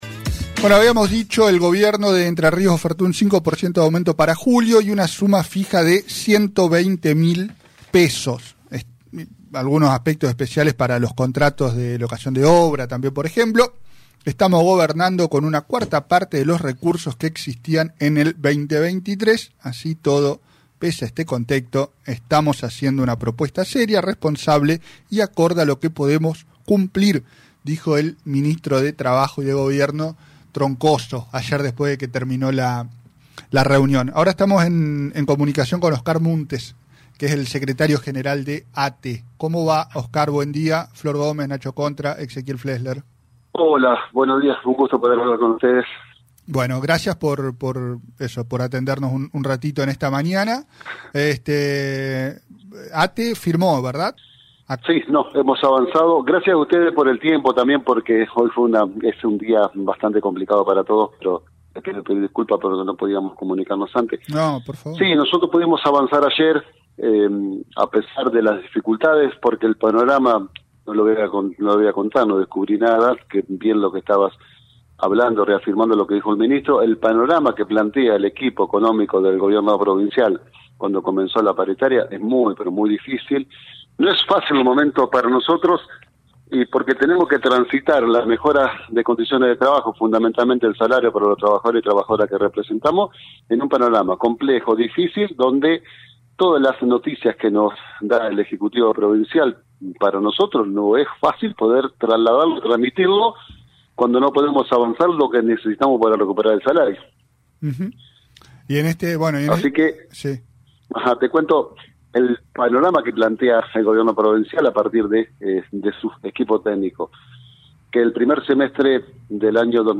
La oferta incluye 5% de aumento con los haberes de julio y una suma fija de 120.000pesos en tres pagos. Entrevista